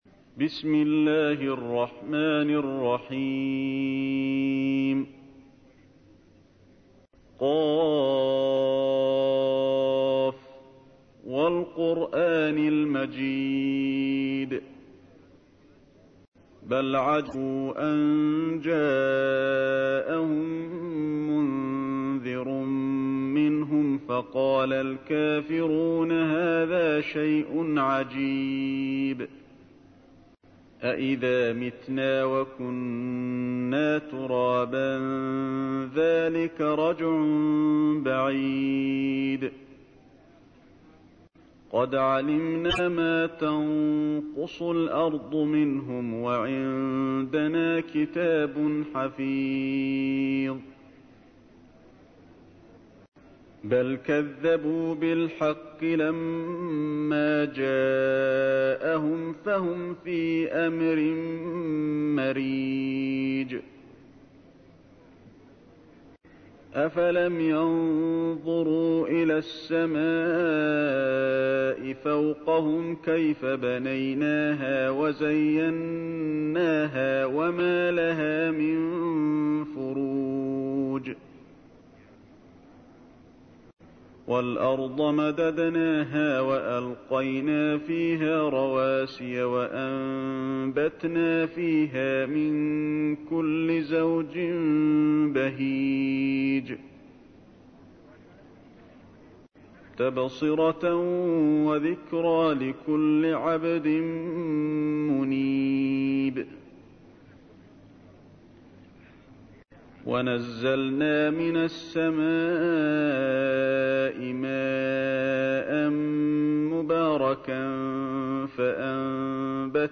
تحميل : 50. سورة ق / القارئ علي الحذيفي / القرآن الكريم / موقع يا حسين